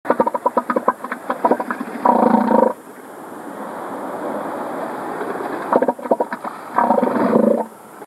바다코끼리울음소리를 내는 수컷 바다코끼리
바다코끼리 소리